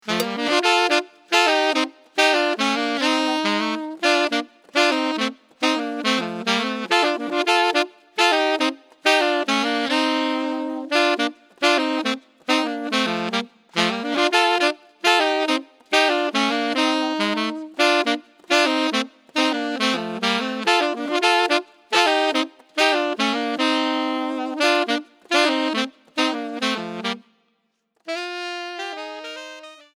2 Saxophones